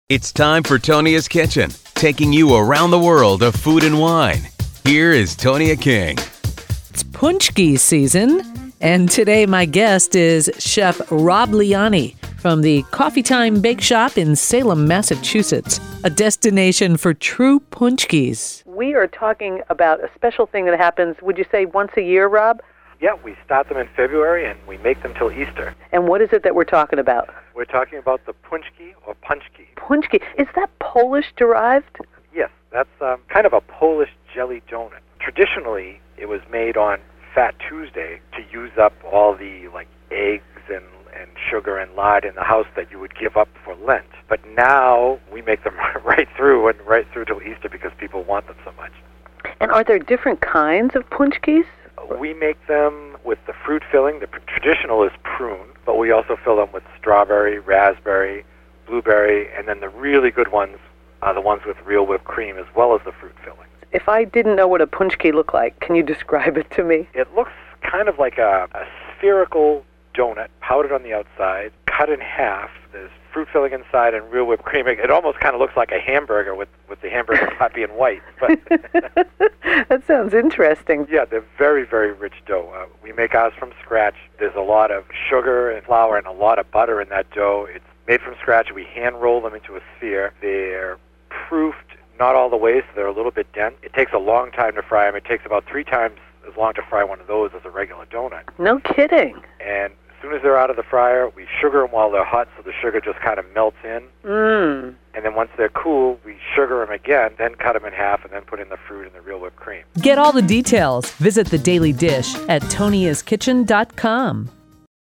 Pronounced, pooch-kie, a Paczki is essentially Polish jelly doughnut, extremely popular this time of year.